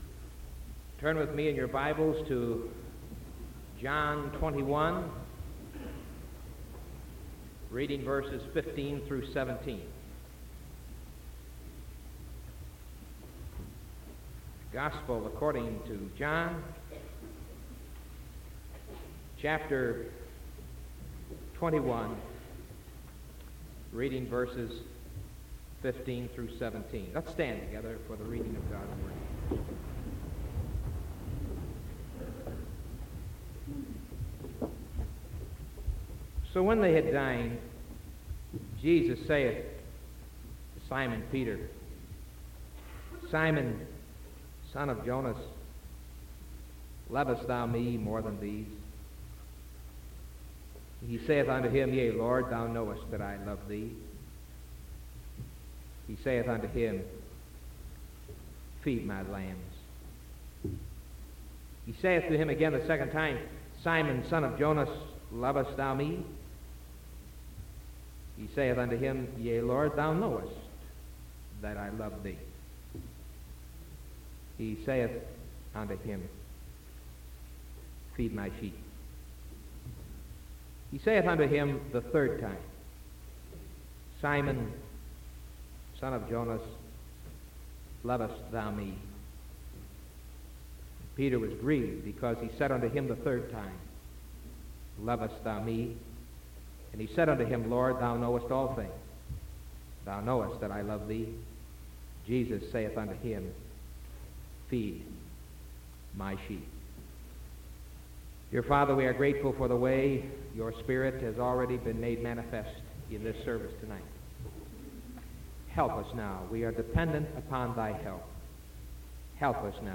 Sermon March 24th 1974 PM